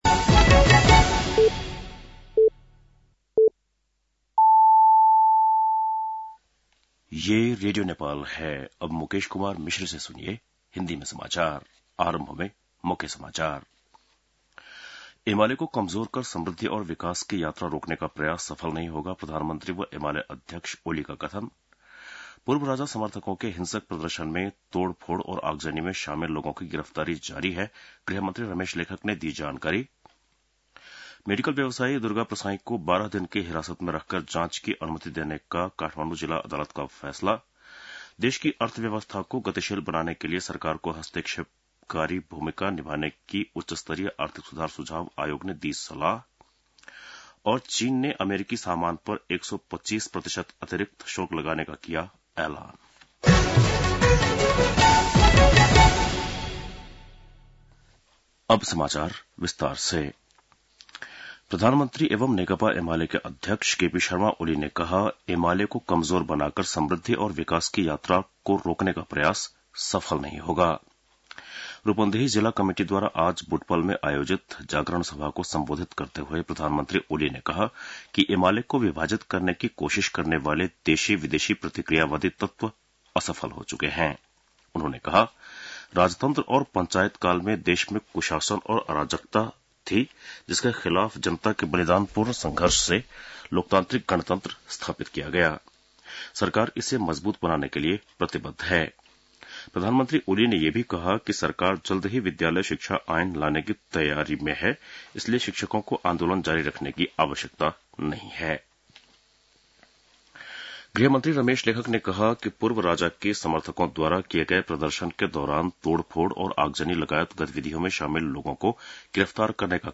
बेलुकी १० बजेको हिन्दी समाचार : २९ चैत , २०८१